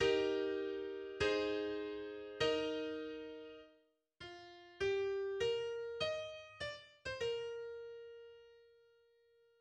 The eventual goal of the symphony, E-flat major, is briefly hinted at after rehearsal 17, with a theme in the trumpets that returns in the finale.